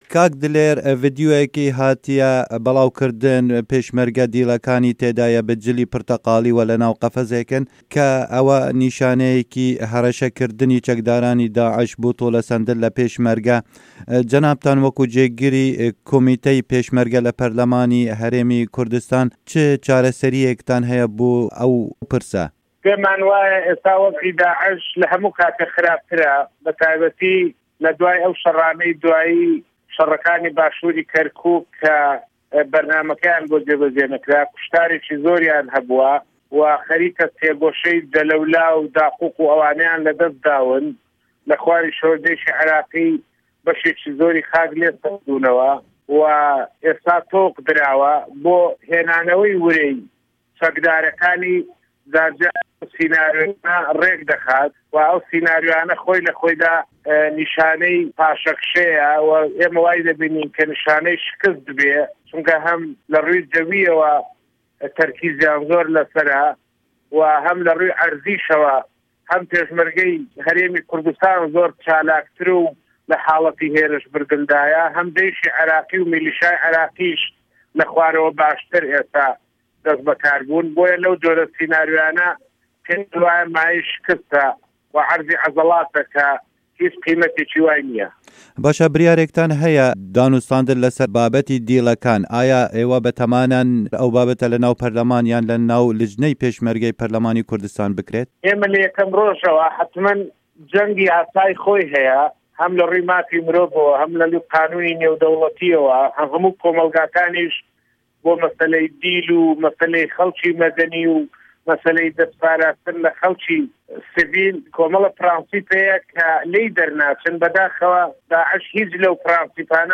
Hevpeyvin digel Dîlêr Mawetî